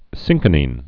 (sĭngkə-nēn, sĭnchə-)